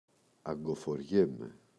αγκοφοριέμαι [aŋgofoꞋrʝeme]
Αγκοφοριέμαι.mp3